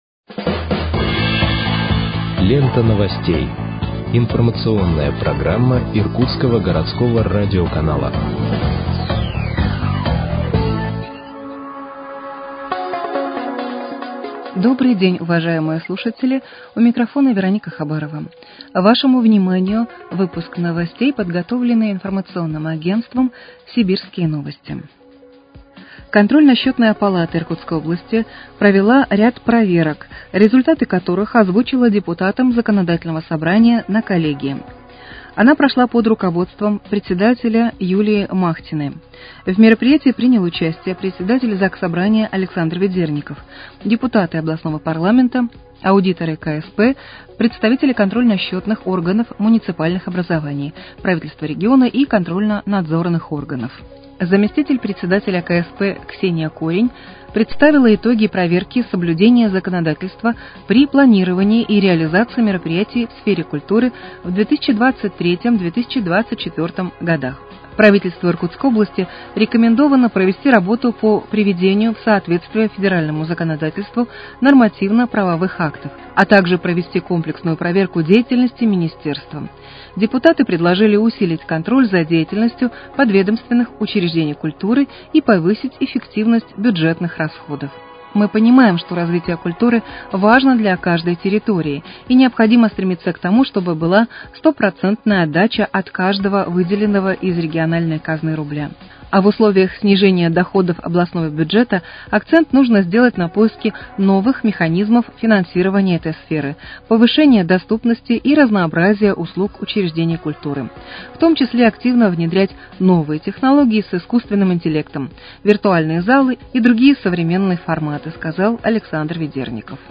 Выпуск новостей в подкастах газеты «Иркутск» от 4.12.2025 № 2